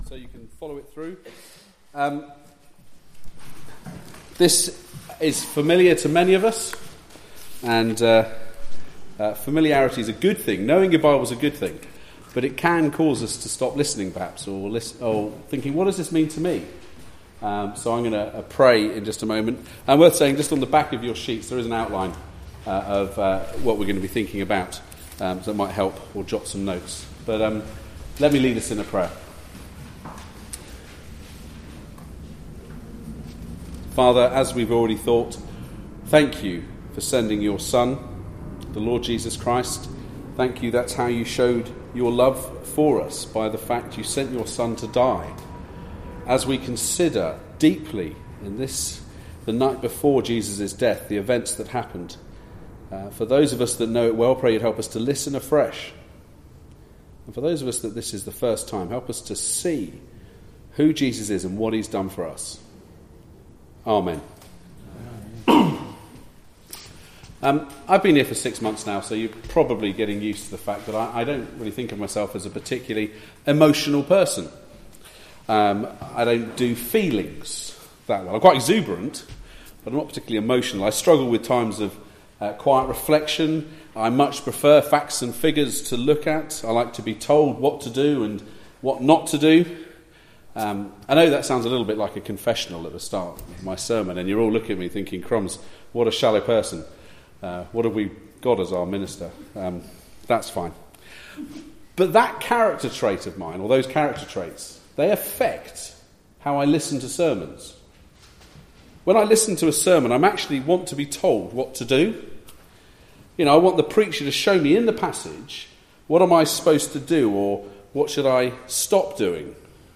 Mark:13 28-58 Service Type: Weekly Service at 4pm Bible Text